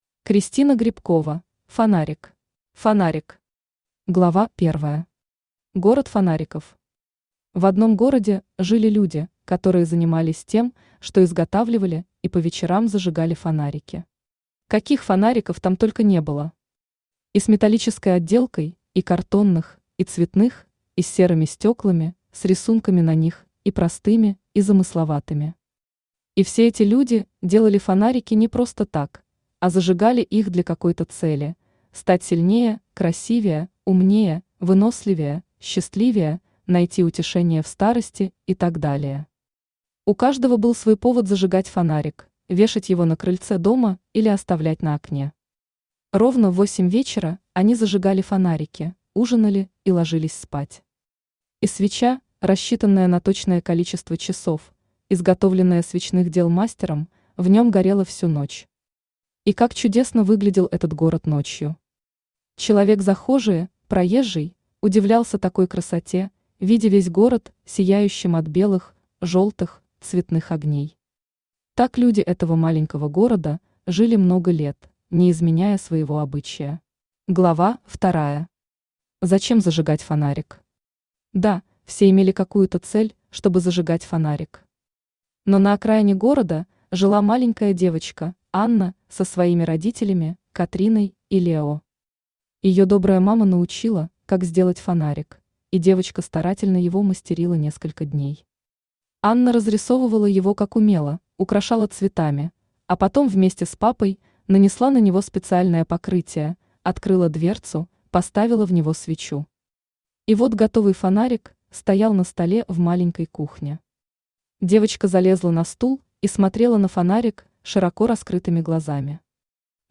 Аудиокнига Фонарик | Библиотека аудиокниг
Aудиокнига Фонарик Автор Кристина Грибкова Читает аудиокнигу Авточтец ЛитРес.